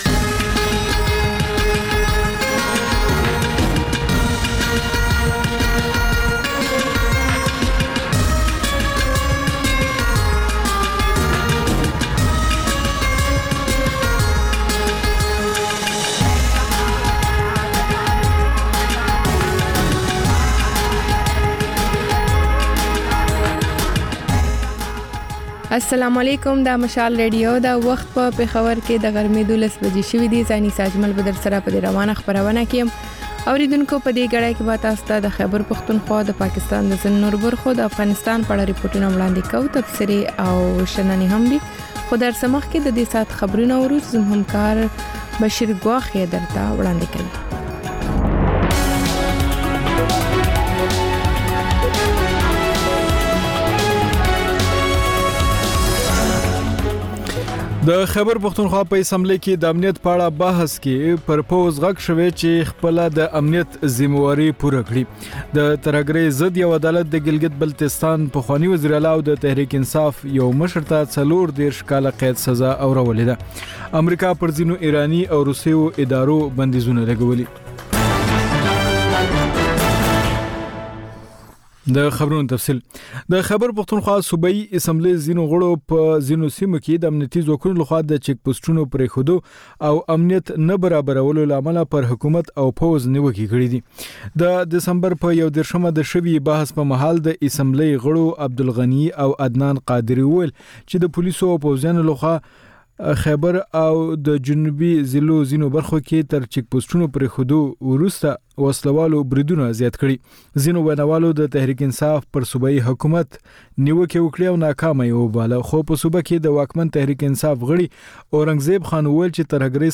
د مشال راډیو د ۱۴ ساعته خپرونو په لومړۍ خبري ګړۍ کې تازه خبرونه، رپورټونه، شننې، مرکې او کلتوري، فرهنګي رپورټونه خپرېږي.